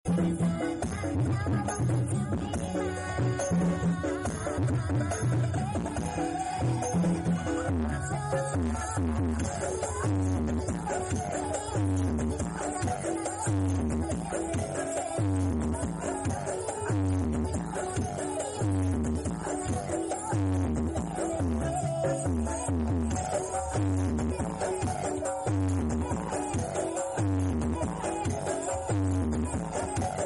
Peserta karnaval karangrejo garum 2025 sound effects free download